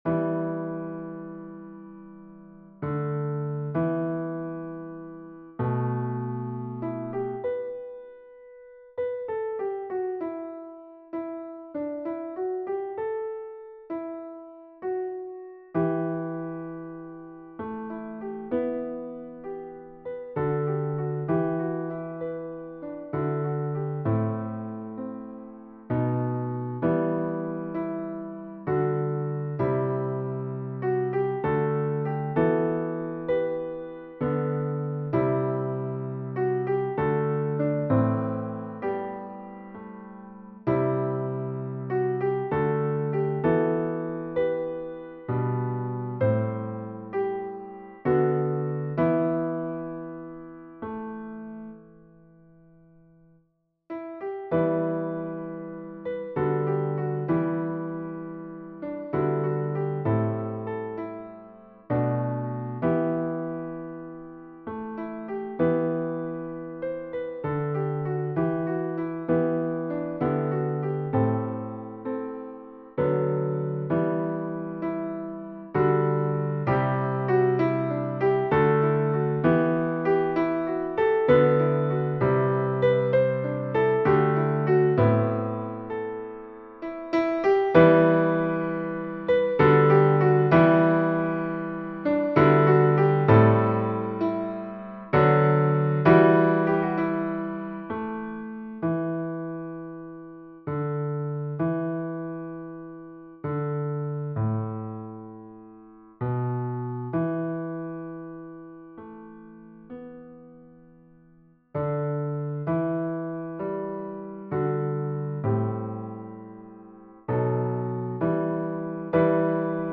Versions "piano"
Basse